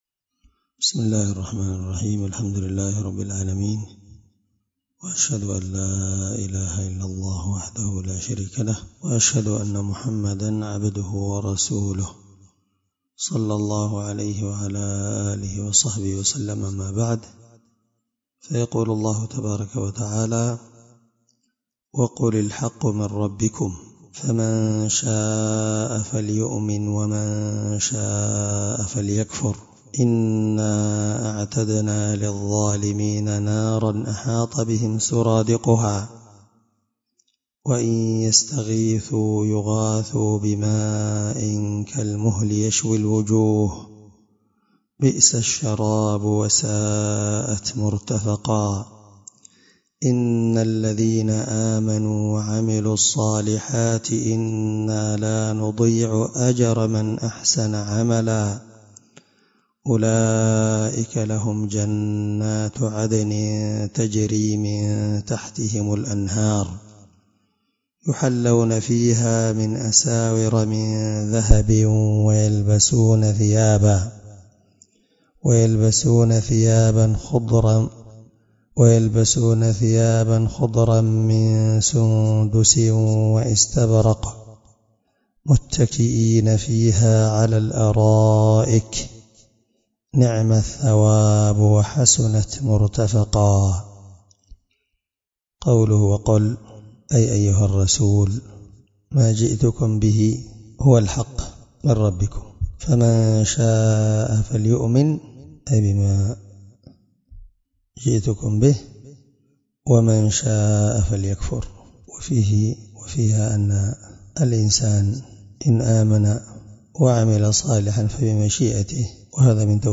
الدرس13 تفسير آية (29-31) من سورة الكهف